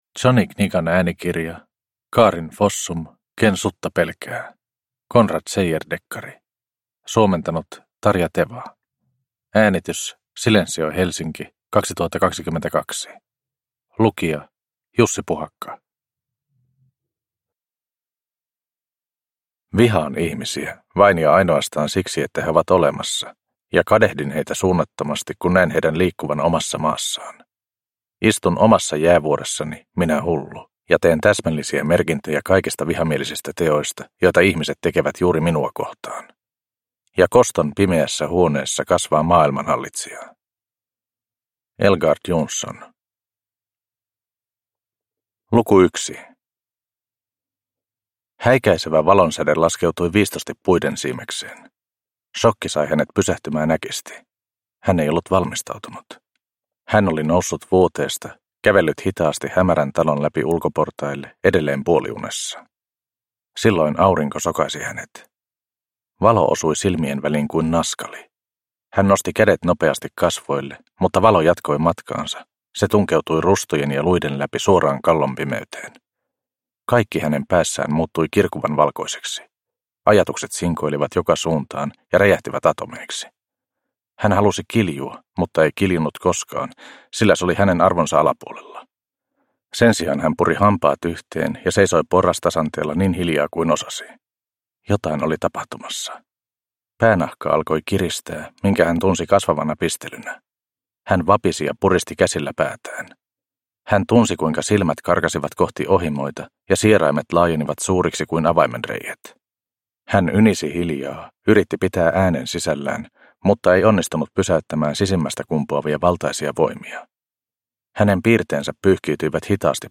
Ken sutta pelkää – Ljudbok – Laddas ner